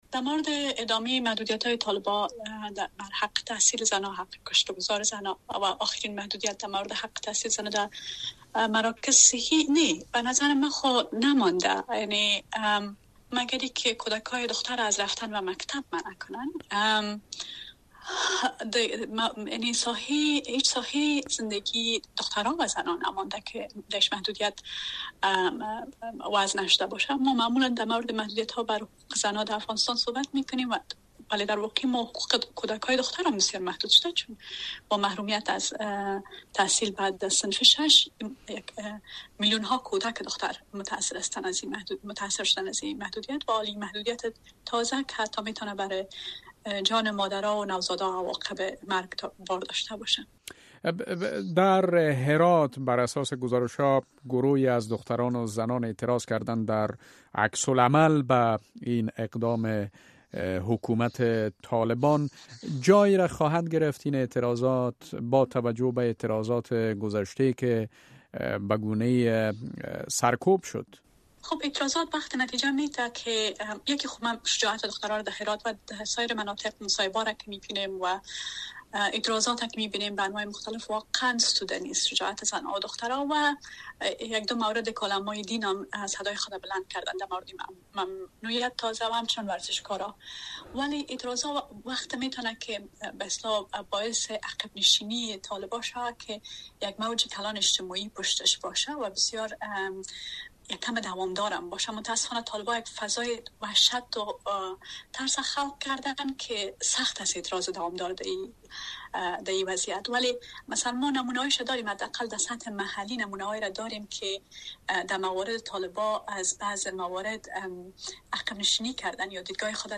شهرزاد اکبر، مسئول سازمان حقوق بشری « رواداری»